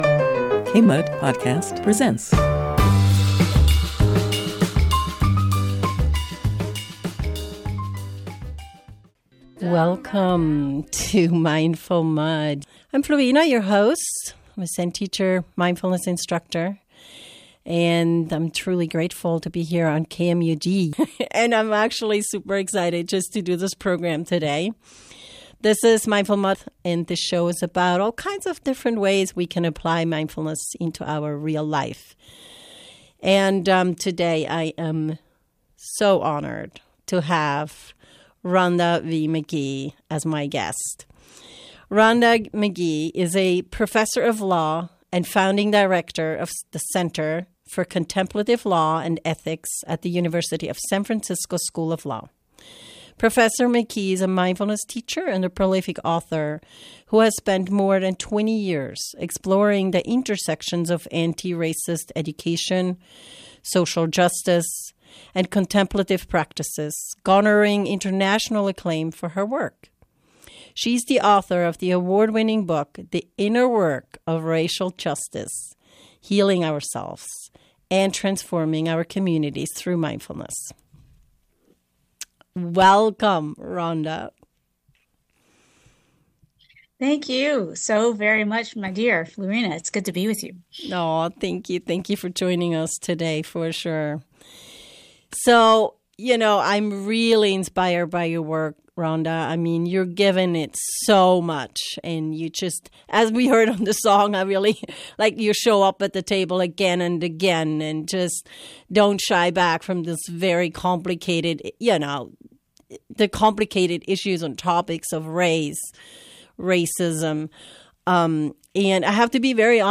In this episode, we have a conversation about how we can raise awareness in ourselves and in our environment about our own biases. We explore what builds separation and how to confront racial injustice and move towards healing and hope together.&nbsp; You will hear from different community voices as this lively conversation unfolds.